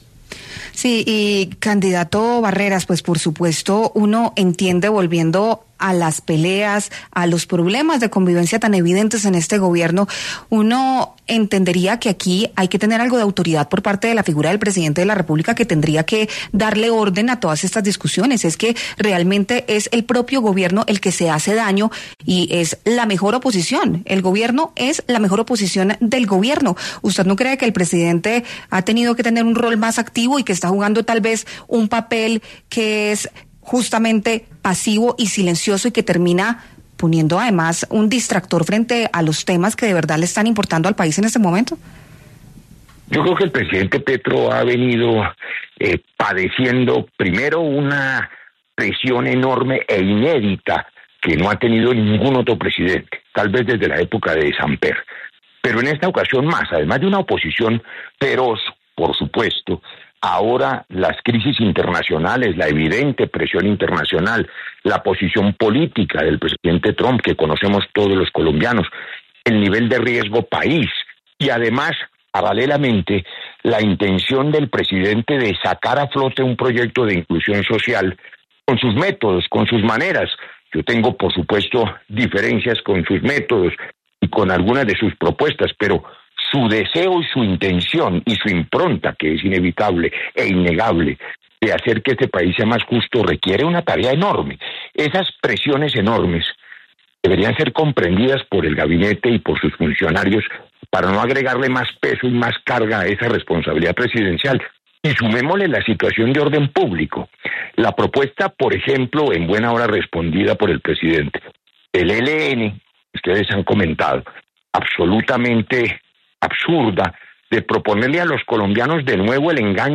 El precandidato presidencial, Roy Barreras, pasó por los micrófonos de 6AM W de Caracol Radio, para hablar sobre la paz total y el intento de diálogo con el ELN.